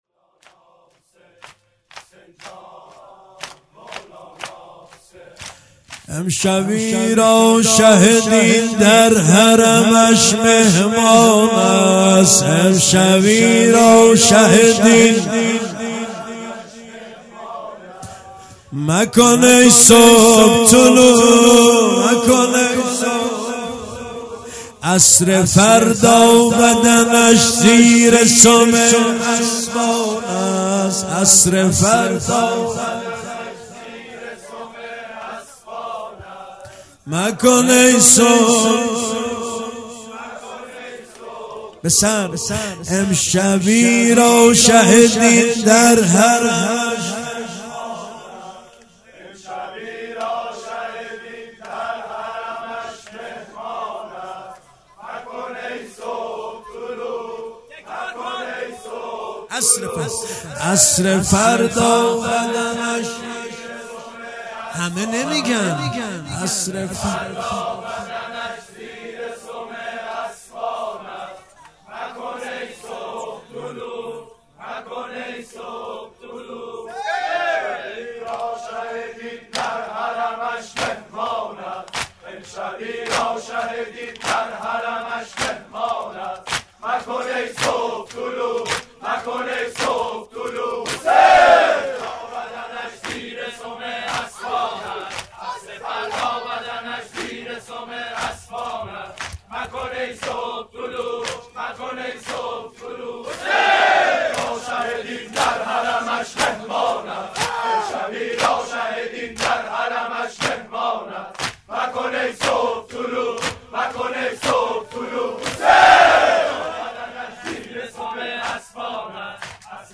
شب دهم محرم - دودمه - امشبی را شه دین در حرمش مهمان است مکن ای صبح طلوع
محرم 1397